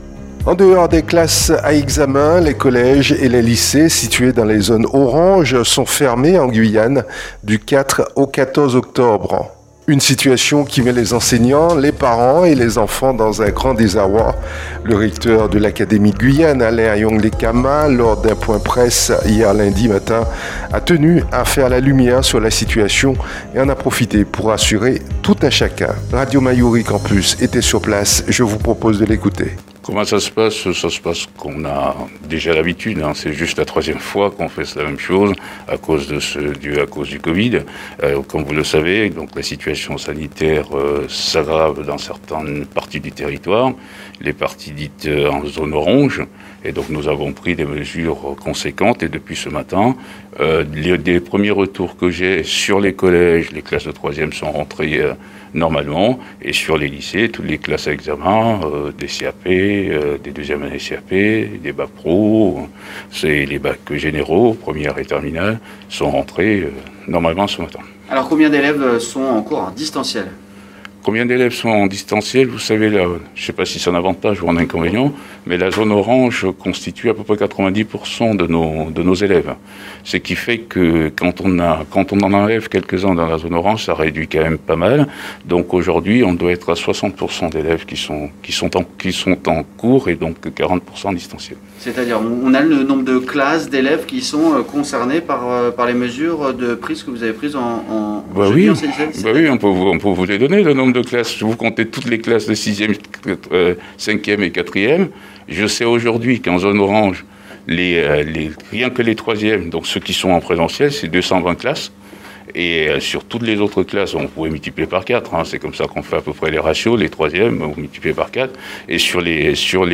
Le recteur de l'académie, Alain Ayong Le Kama, lors d'un point presse, ce lundi matin à tenu à faire la lumière sur la situation et en a profité pour rassurer tout un chacun. Radio Mayouri Campus était sur place